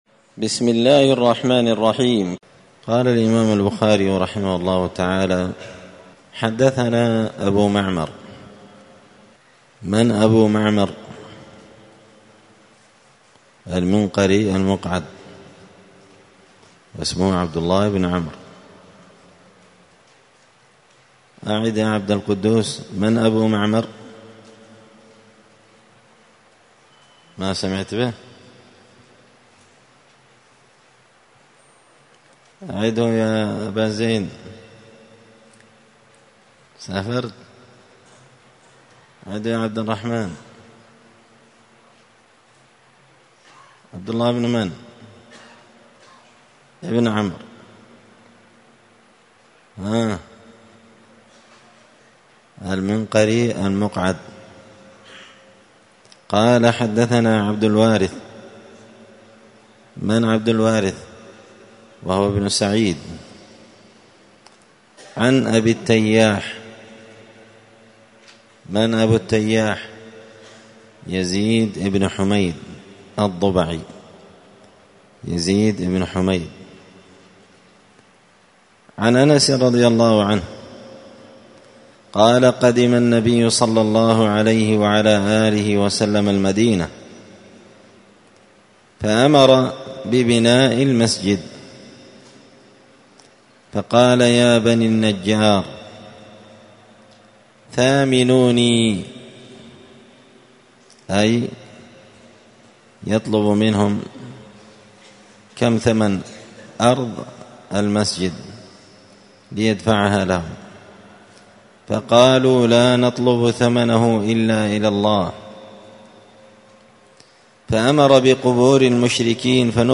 كتاب فضائل المدينة من شرح صحيح البخاري- الدرس 2 تابع لبَابُ حَرَمِ الْمَدِينَةِ.